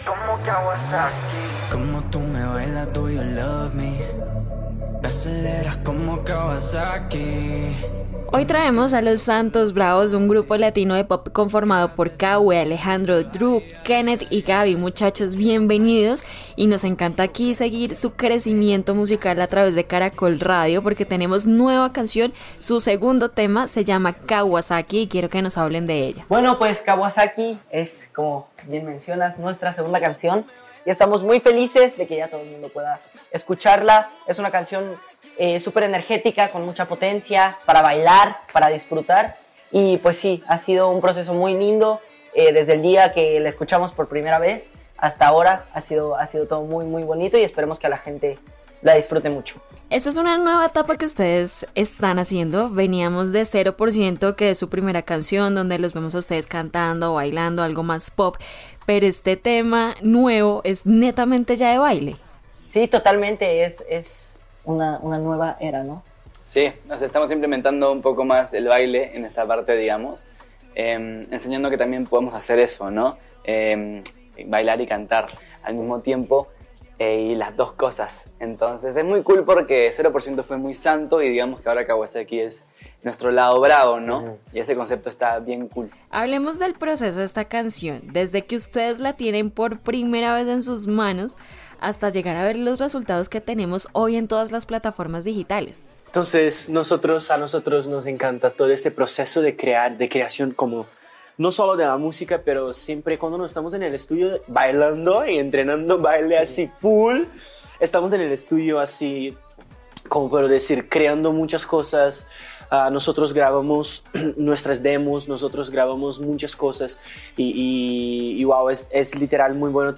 En entrevista con Caracol Radio, hablaron de su más reciente canción titulada “Kawasaki” una mezcla urbano latino con influencias brasileñas, mucha energía y una vibra internacional que define el ADN del grupo.